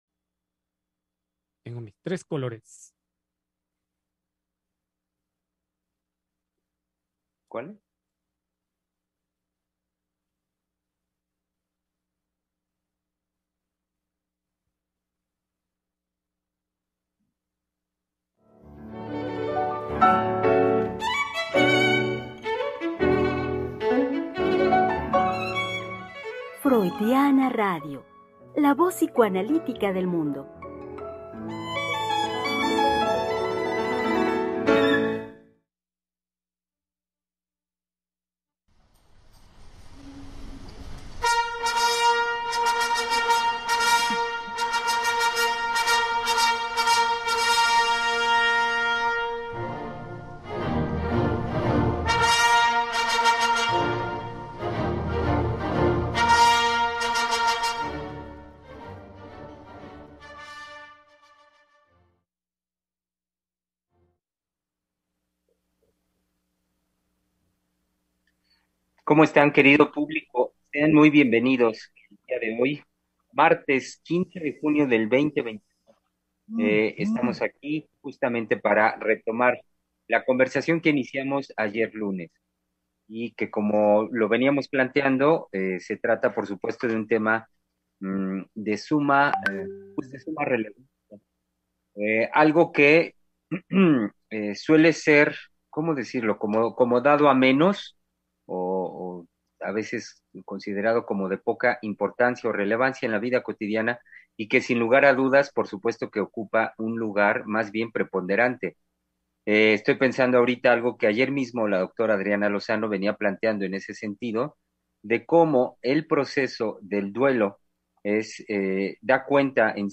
Programa trasmitido el 15 de junio del 2021.